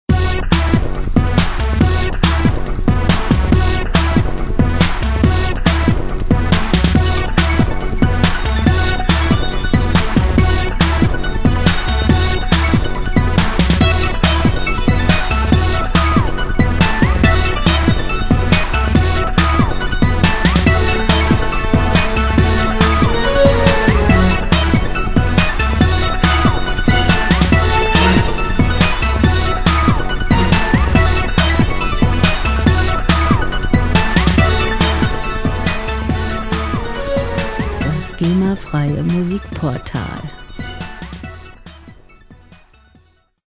• Electroclash